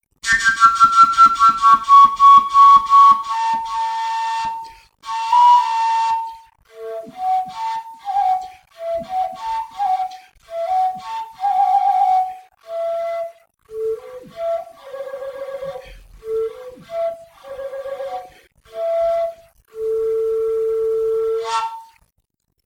Fujara nemá taký bohatý rozfuk, ako možno počuť u fujár vyrobených profesionálmi.
Hlas fujarky je taký trochu zamatový, ale na druhej strane veľmi príjemný - veď posúďte sami:
Zvuk fujarky
fujara.mp3